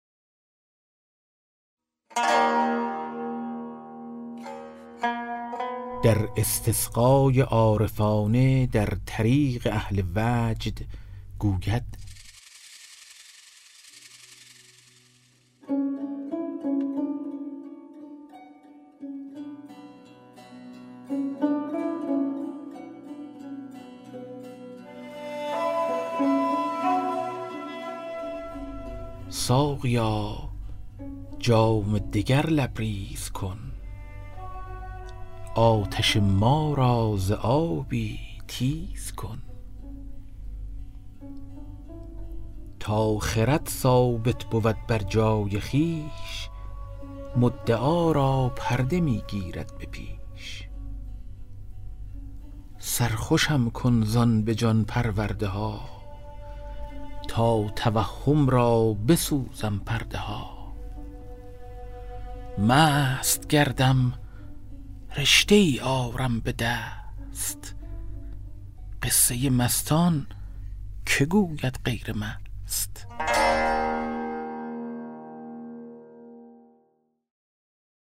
کتاب صوتی گنجینه الاسرار، مثنوی عرفانی و حماسی در روایت حادثه عاشورا است که برای اولین‌بار و به‌صورت کامل در بیش از ۴۰ قطعه در فایلی صوتی در اختیار دوستداران ادبیات عاشورایی قرار گرفته است.